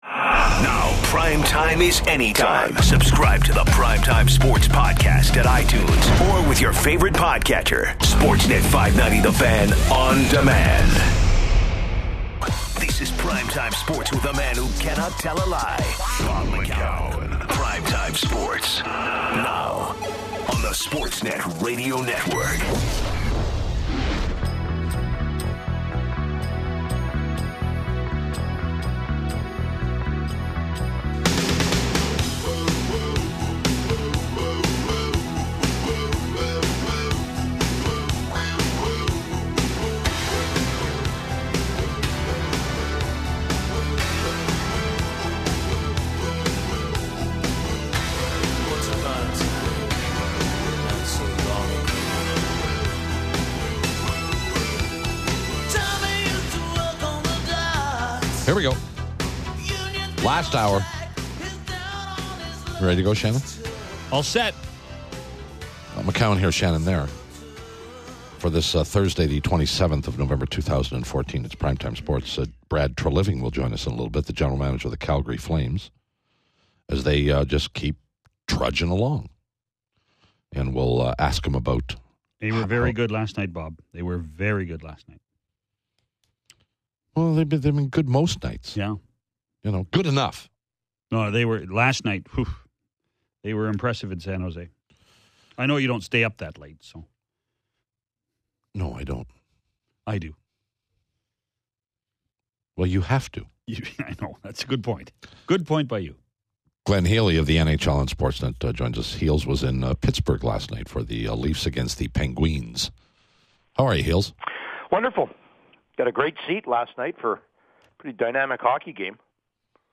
Prime Time Sports with Bob McCown [Podcast] - 44:39 mins - Interview starts @ 28:40